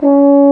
Index of /90_sSampleCDs/Roland LCDP12 Solo Brass/BRS_Baritone Hrn/BRS_Euphonium
BRS BARI C#3.wav